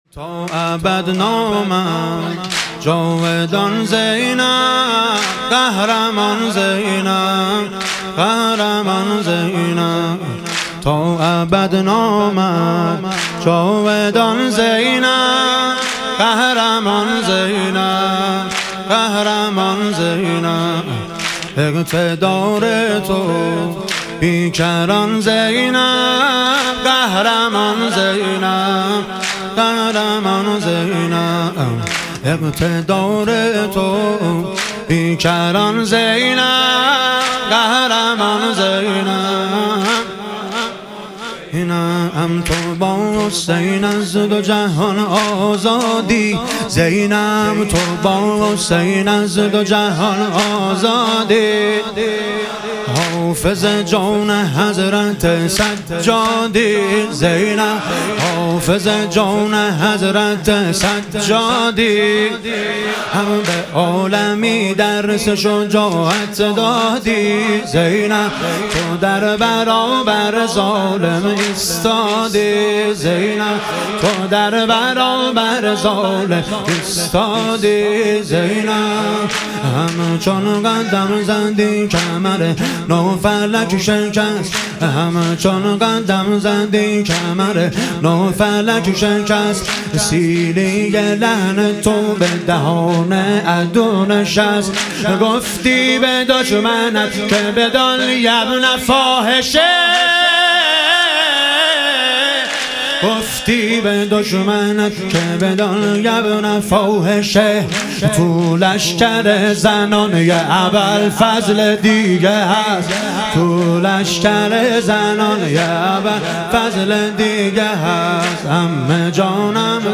شهادت حضرت زینب کبری علیها سلام - واحد